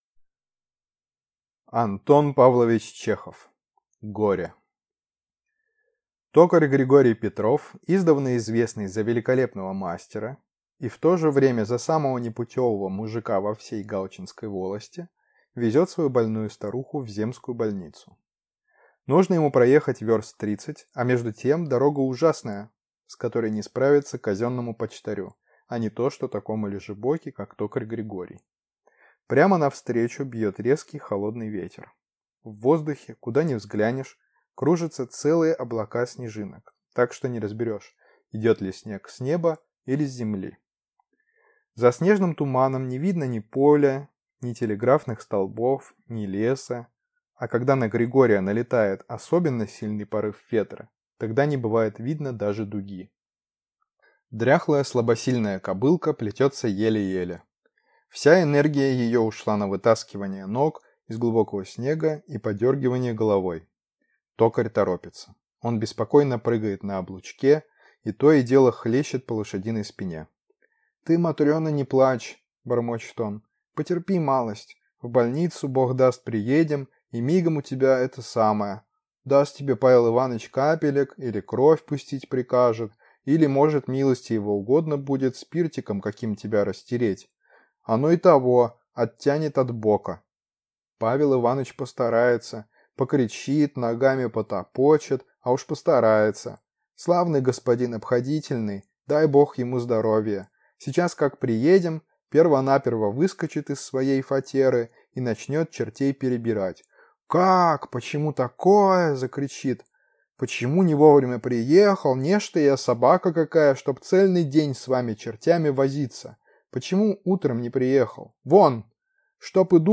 Аудиокнига Горе | Библиотека аудиокниг